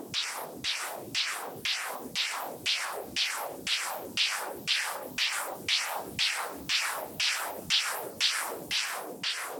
STK_MovingNoiseF-100_03.wav